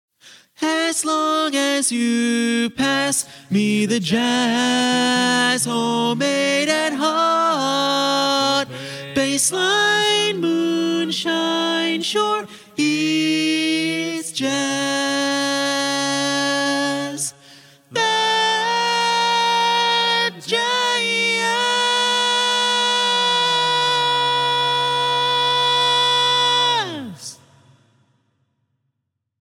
Key written in: D♭ Major
Type: Barbershop